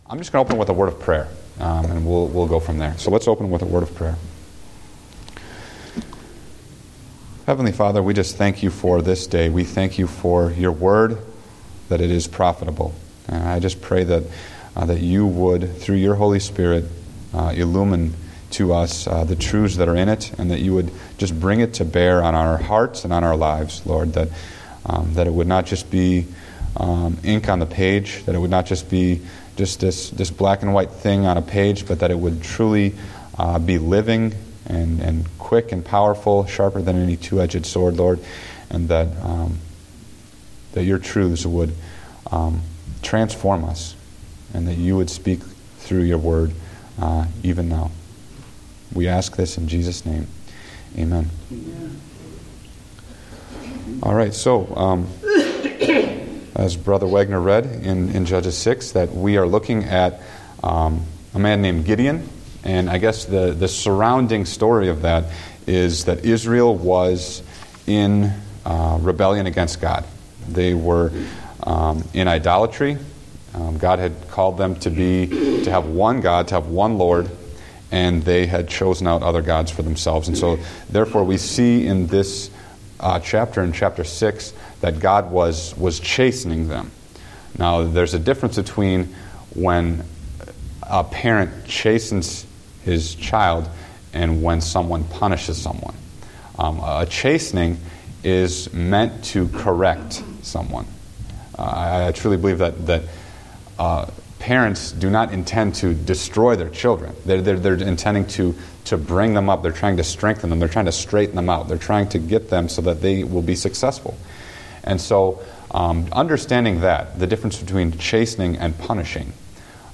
Date: October 12, 2014 (Adult Sunday School)